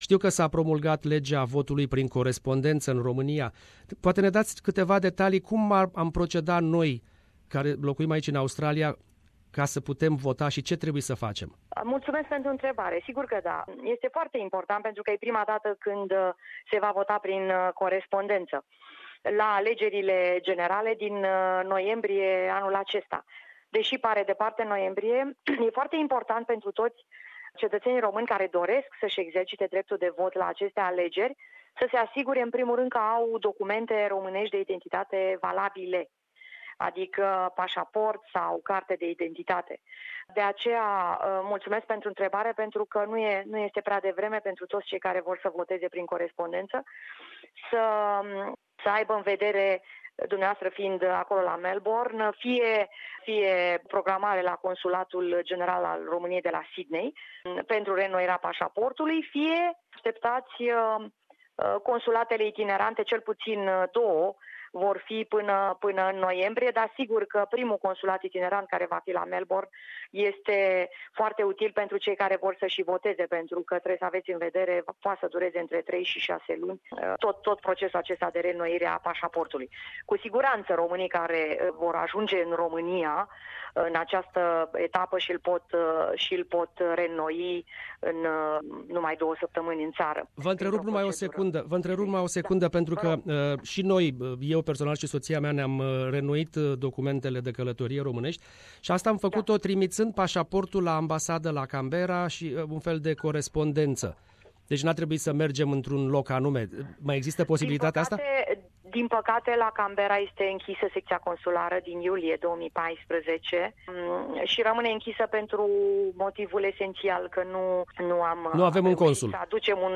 Interviu cu Ambasadoarea Romaniei in Australia si Noua Zeelanda. Precizari importante despre votul prin corespondenta.